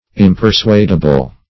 Search Result for " impersuadable" : The Collaborative International Dictionary of English v.0.48: Impersuadable \Im`per*suad"a*ble\, a. [Cf. Impersuasible .] Not to be persuaded; obstinate; unyielding; impersuasible.